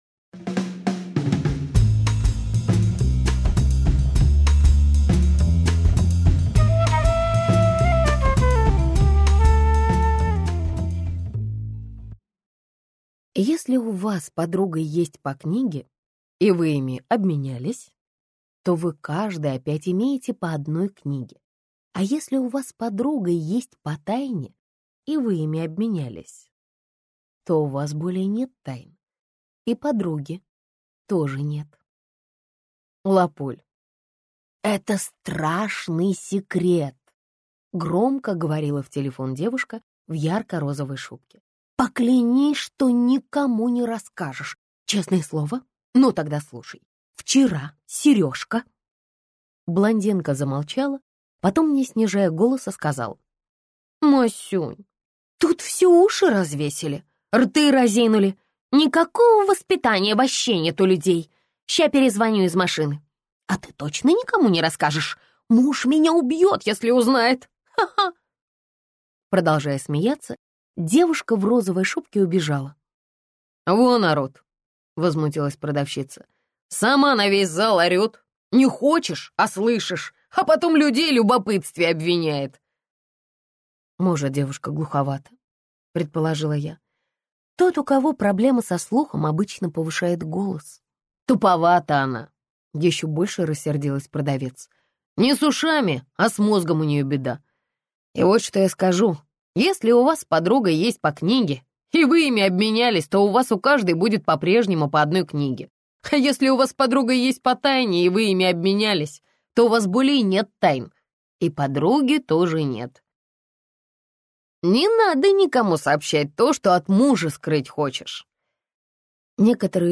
Аудиокнига Гимназия неблагородных девиц - купить, скачать и слушать онлайн | КнигоПоиск